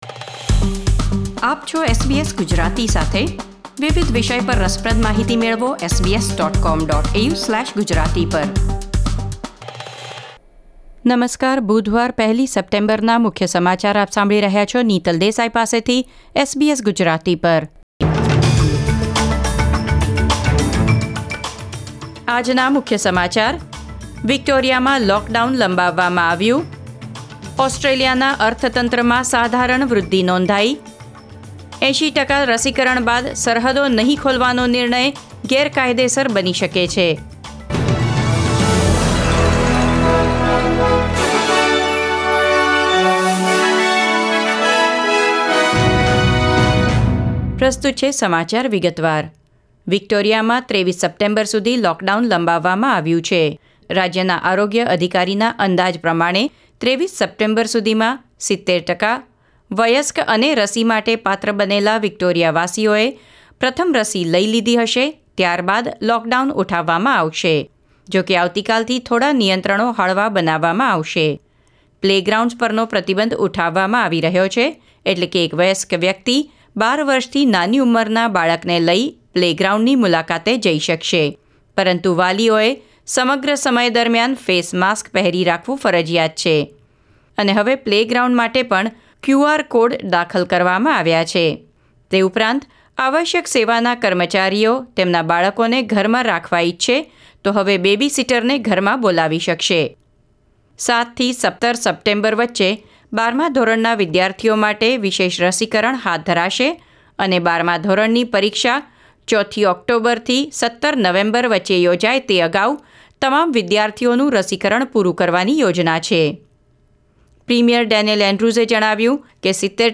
SBS Gujarati News Bulletin 1 September 2021